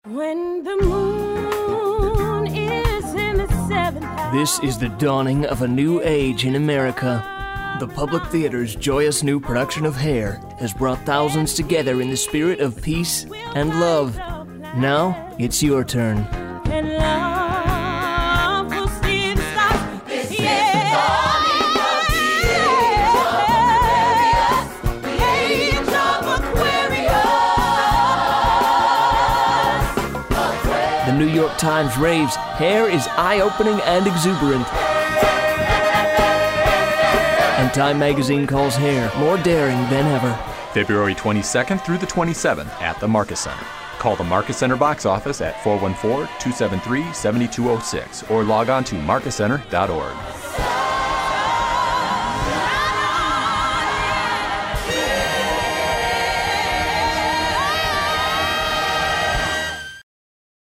Hair Radio Commercial